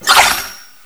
cry_not_meltan.aif